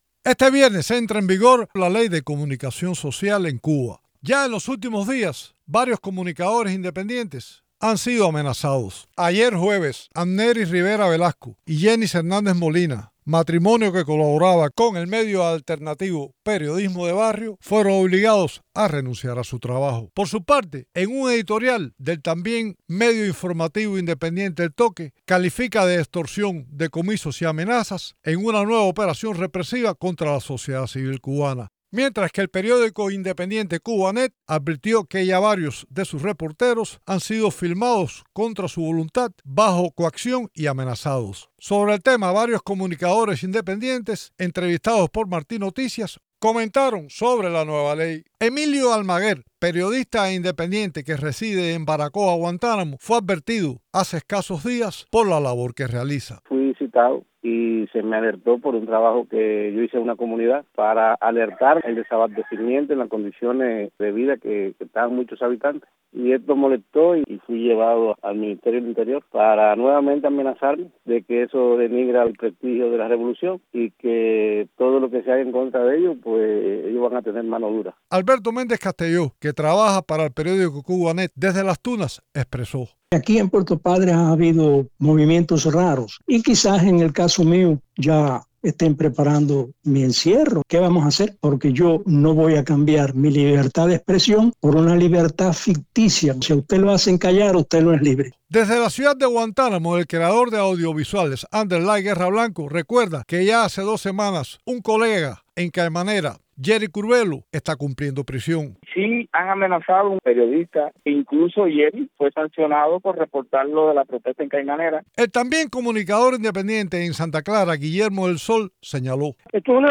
Periodistas independientes reaccionan a la entrada en vigor de la nueva norma.